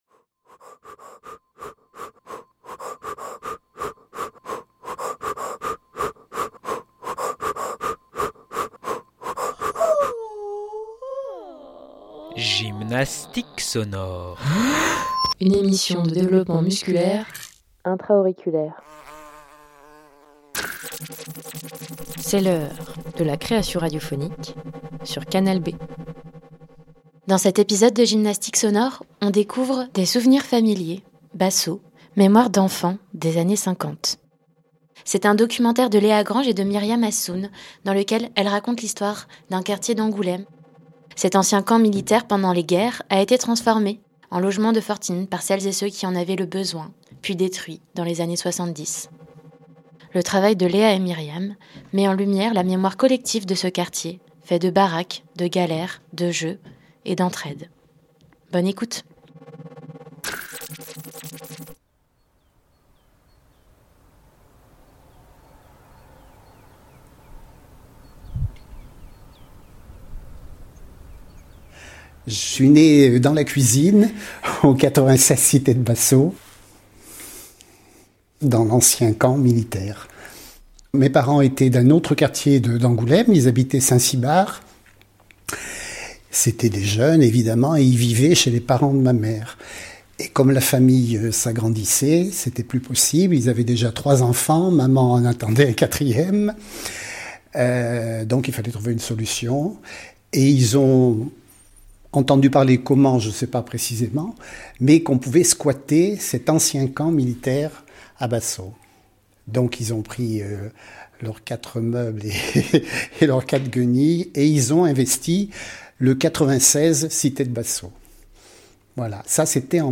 Ce documentaire collecte, fixe et archive cette mémoire, en s’appuyant sur la parole de ceux et celles qui la portent et l’entretiennent encore.